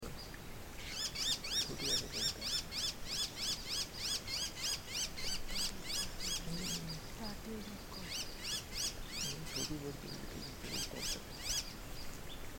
Variegated Laughingthrush
Trochalopteron lineatum